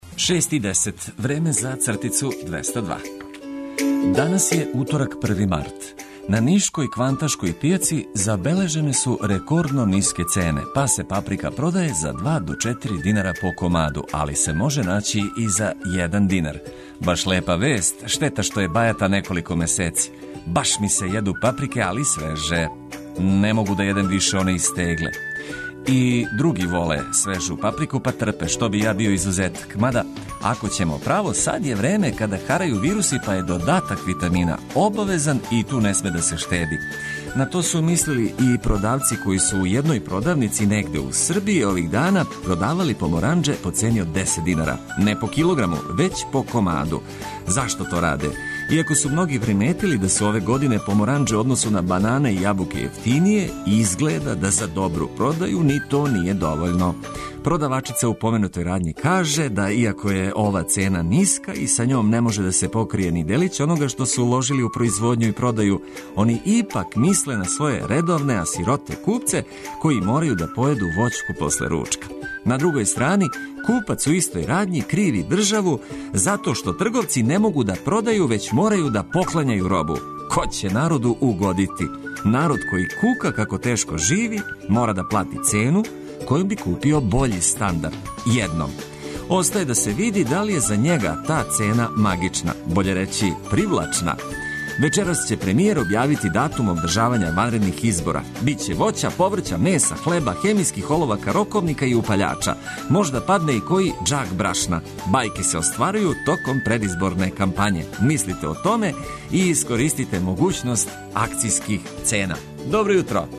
Сервис 202, Квака, Много смо најјачи, Стоп телекс 202 и много добре музике која мотивише олакшаће почетак дана. И овога јутра Лутајући репортер је на улицама главног града и јавља утиске.